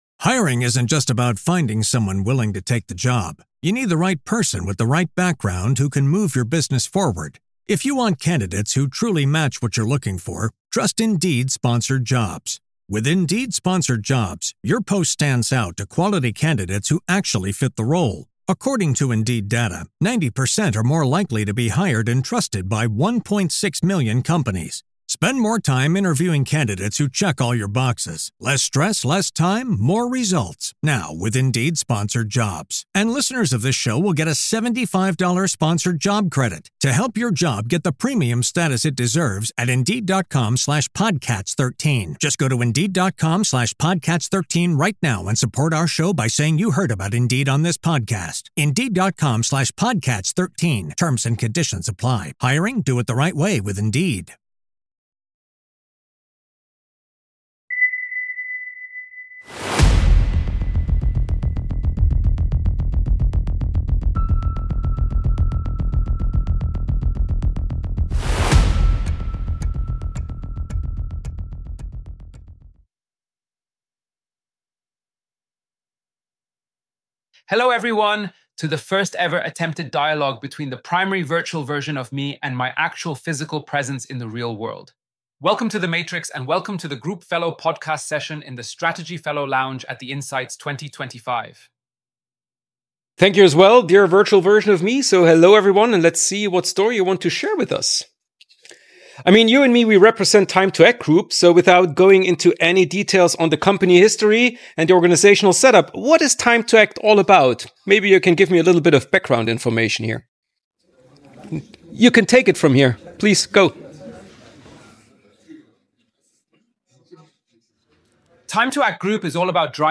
Insights 2025 - Live Podcast Session mit mir und mir ~ IT Skunk Works - ITAM and More Podcast